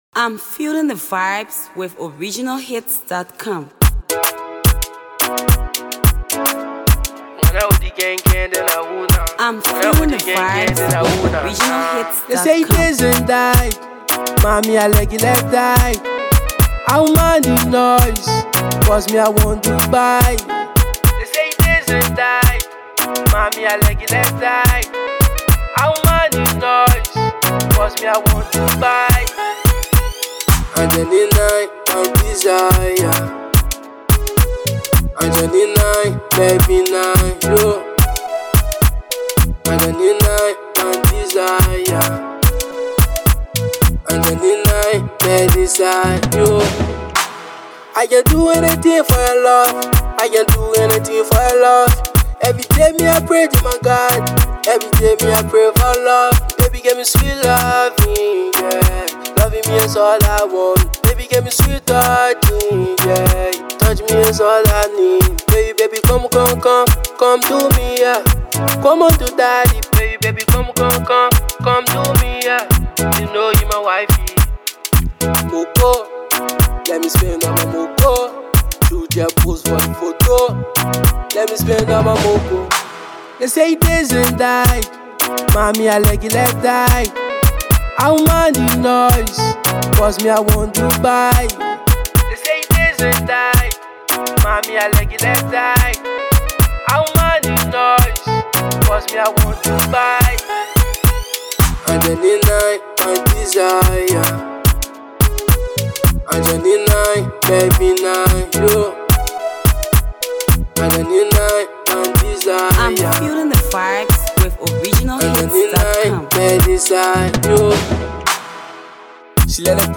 A nice love song